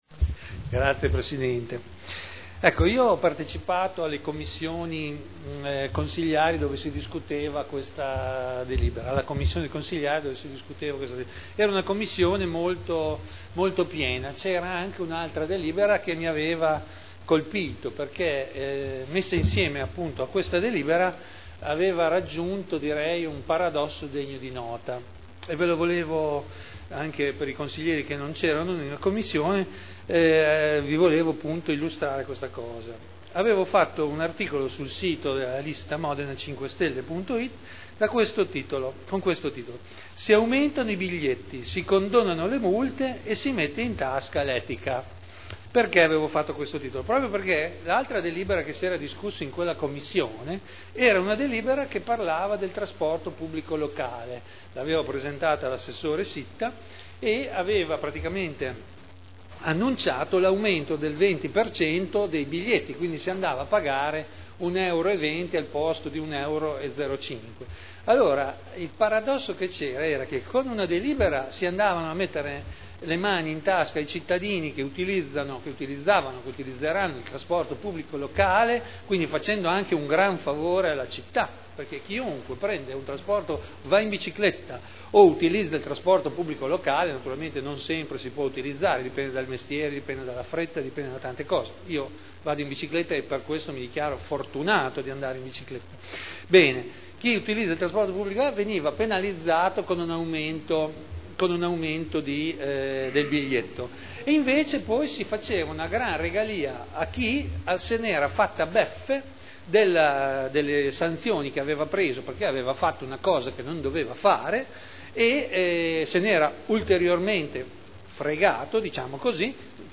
Seduta del 20/12/2010. Dibattito su delibera: Definizione agevolata dei debiti derivanti da sanzioni relative a verbali di accertamento di violazioni al codice della strada elevati dal 1.1.2000 al 31.12.2004 (art. 15 comma 8 quinquiesdecies legge 3.8.2009 n. 102) (Commissione consiliare del 14 dicembre 2010)